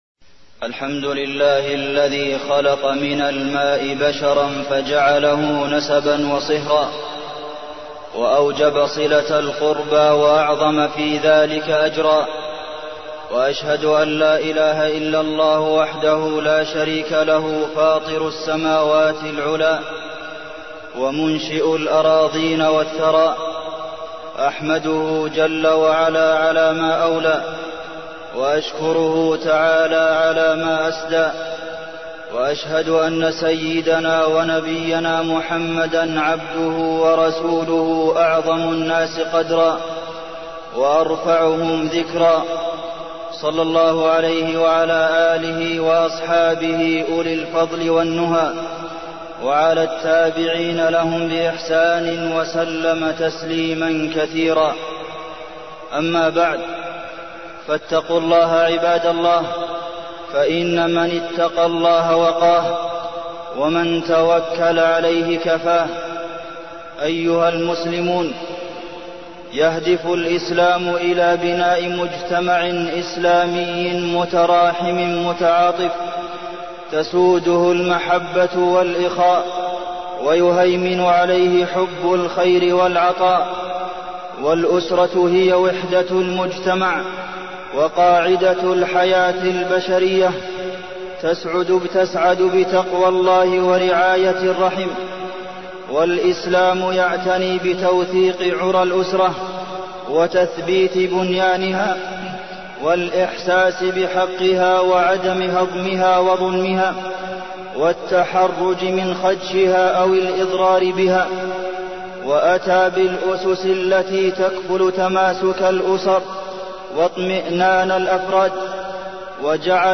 تاريخ النشر ٨ ربيع الثاني ١٤١٩ هـ المكان: المسجد النبوي الشيخ: فضيلة الشيخ د. عبدالمحسن بن محمد القاسم فضيلة الشيخ د. عبدالمحسن بن محمد القاسم صلة الرحم